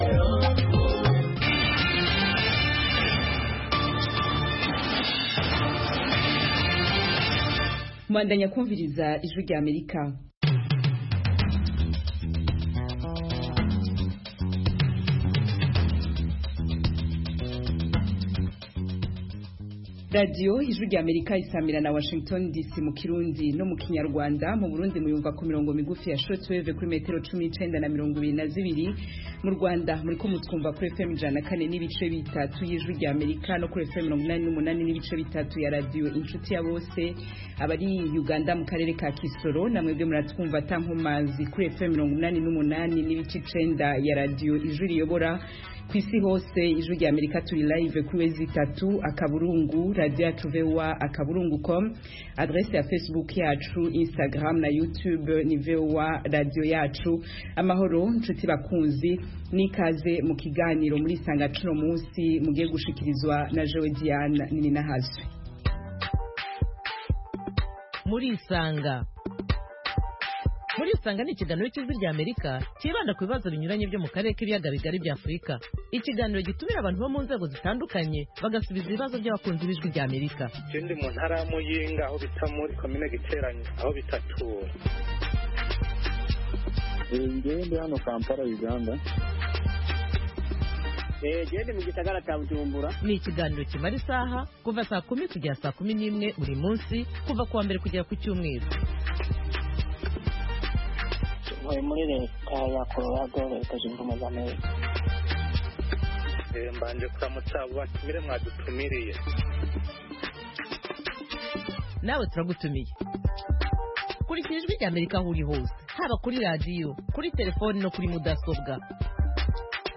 mu kiganiro